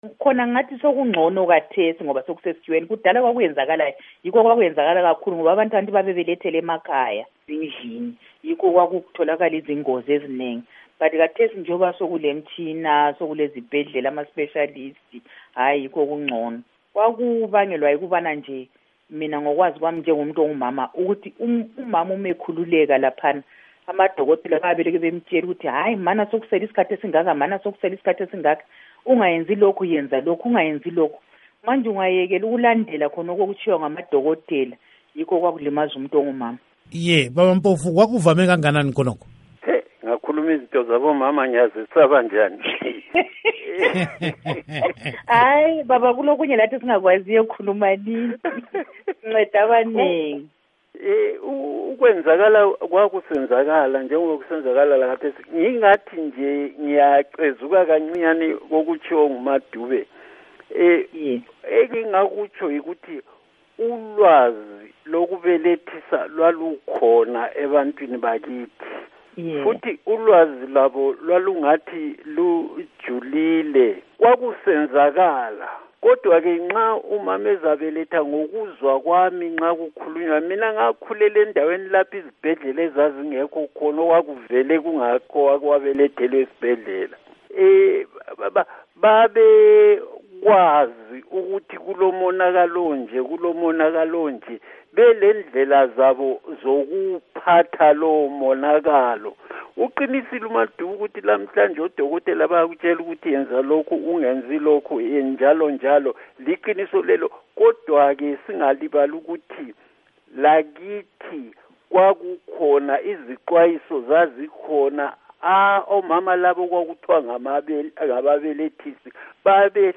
Ingxoxo Esiyenze